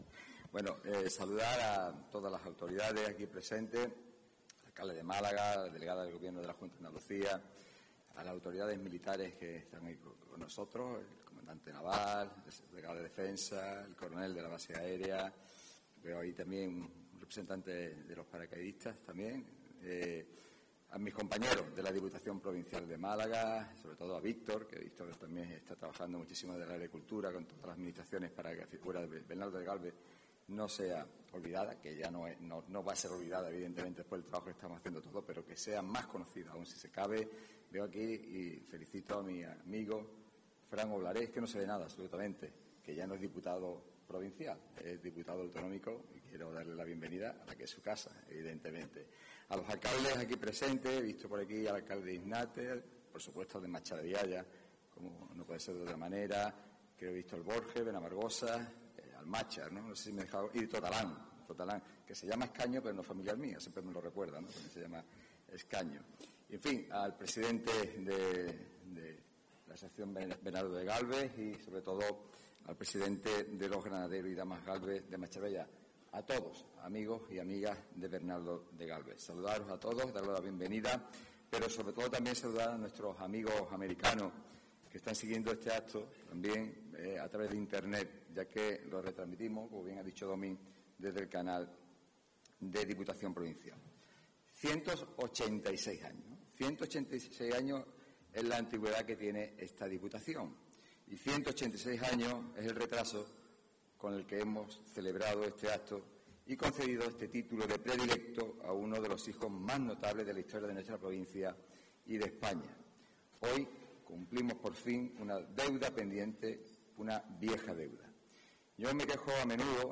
El acto ha tenido lugar en el auditorio Edgar Neville de la Diputación